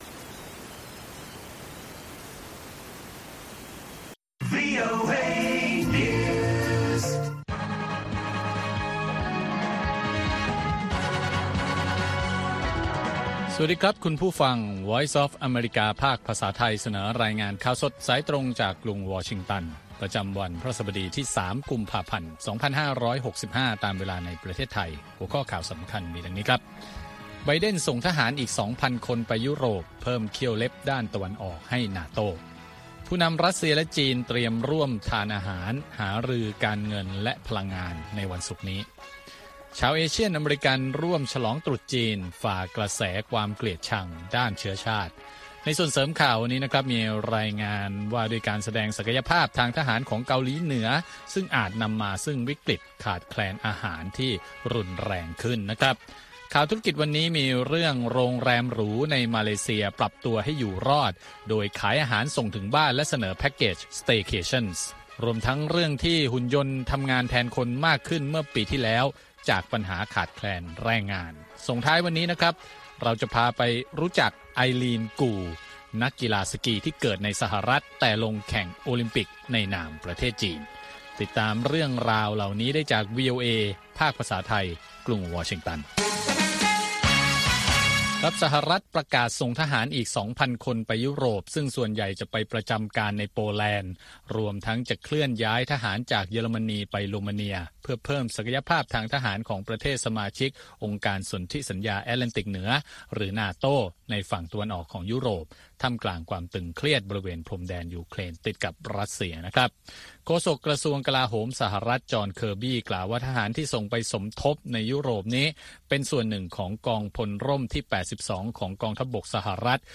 ข่าวสดสายตรงจากวีโอเอ ภาคภาษาไทย 8:30–9:00 น. ประจำวันพฤหัสบดีที่ 3 กุมภาพันธ์ 2565 ตามเวลาในประเทศไทย